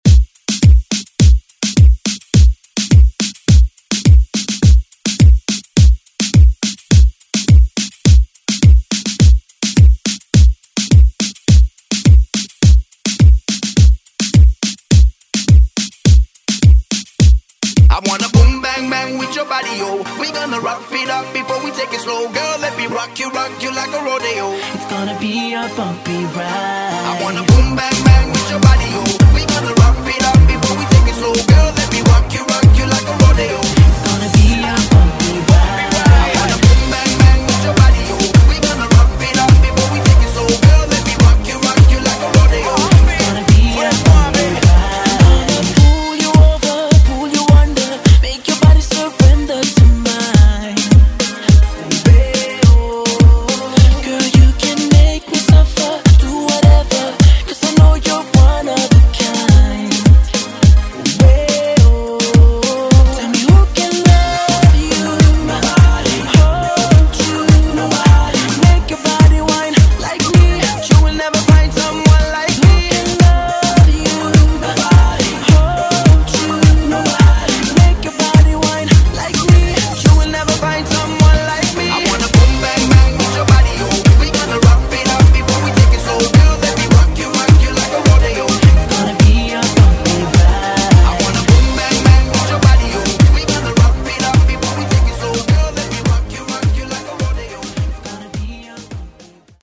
Genre: MOOMBAHTON Version: Clean BPM: 105 Time